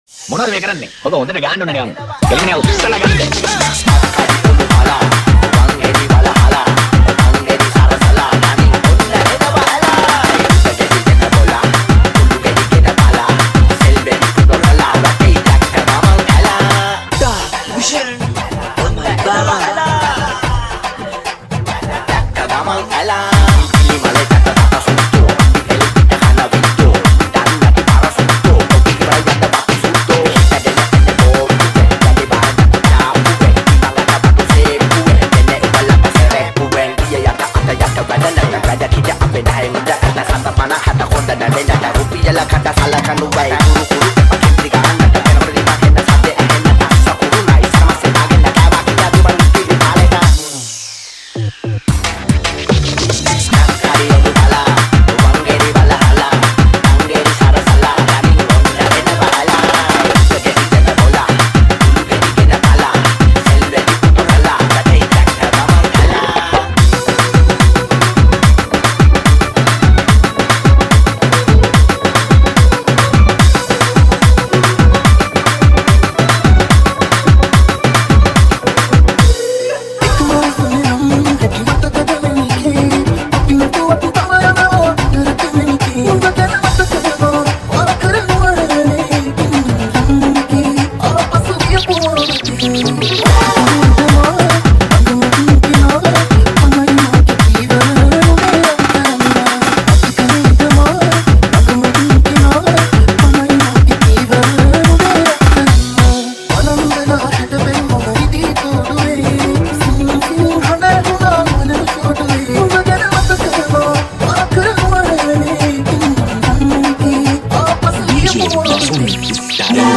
Full Fun Dancing Remix